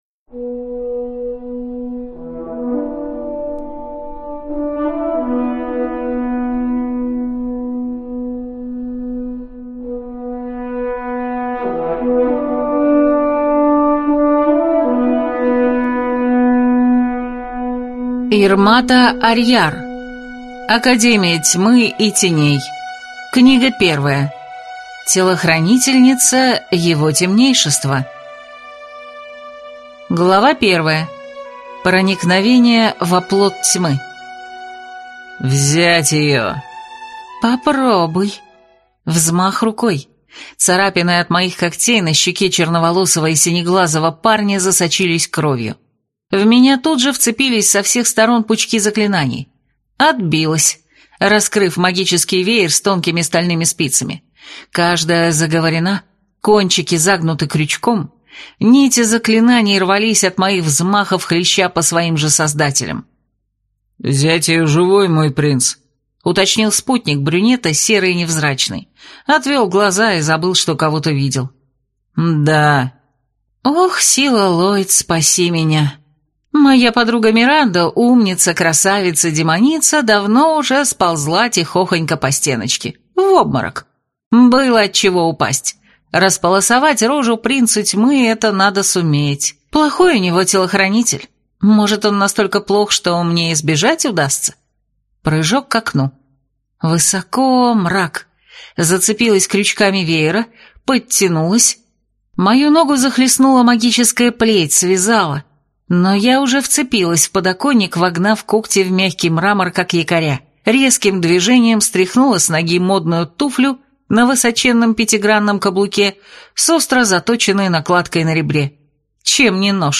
Аудиокнига Академия Тьмы и Теней. Книга 1. Телохранительница его темнейшества | Библиотека аудиокниг